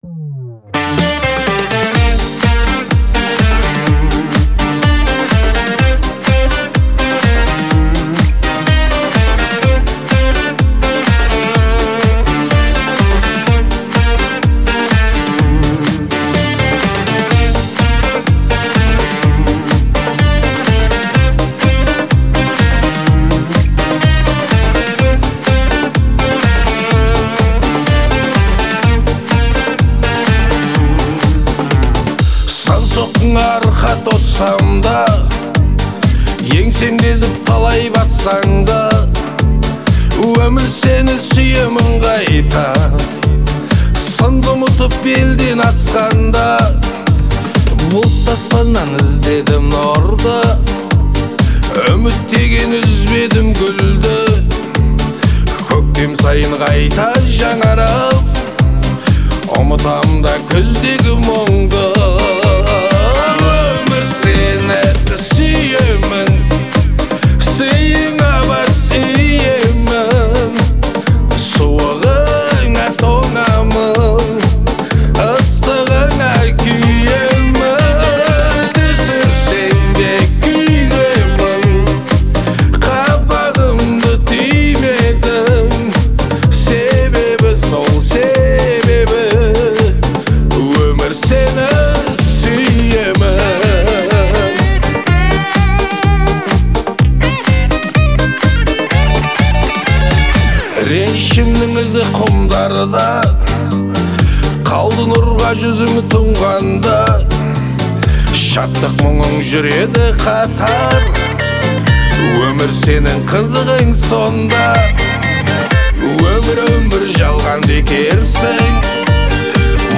используя мелодичные мелодии и выразительное исполнение.